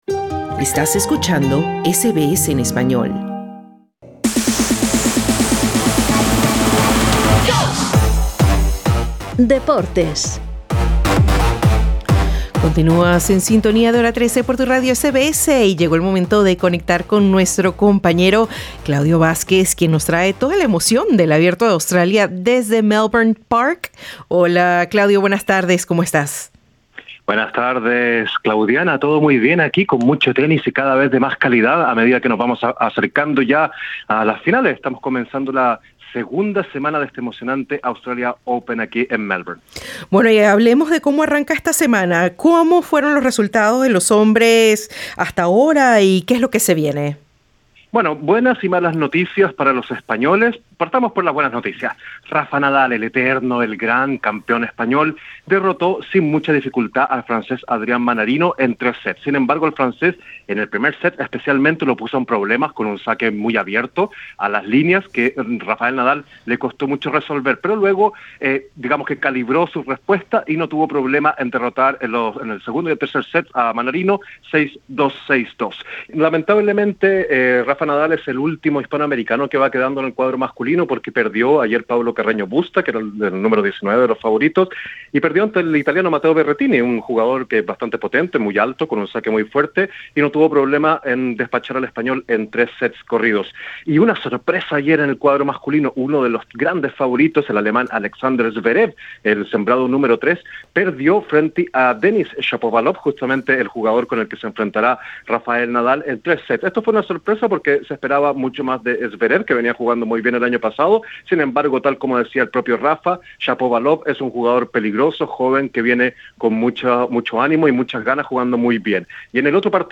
Ganó Rafa Nadal al francés Mannarino y cayó la española Paula Badosa ante Maddison Keys. Escucha esta y otras noticias deportivas del día.